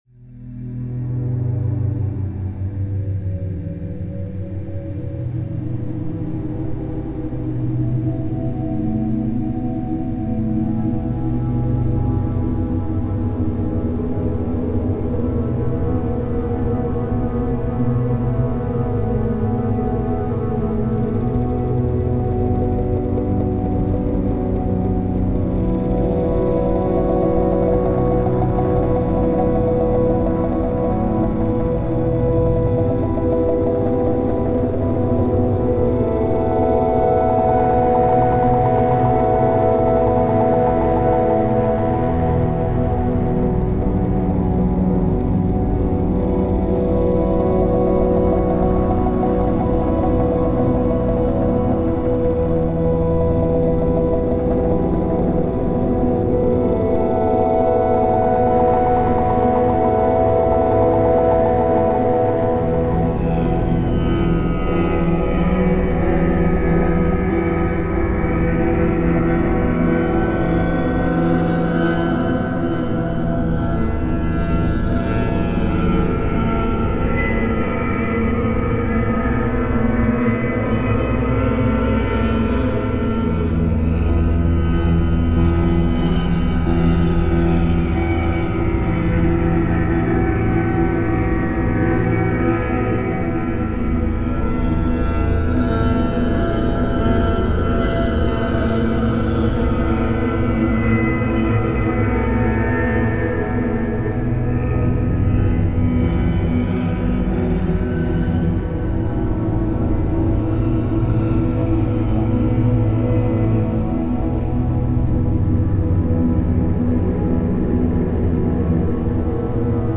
暗い雰囲気のアンビエント楽曲で、購入したけどあまり使用できていなかったシネマティック系のサウンドを中心に作っている。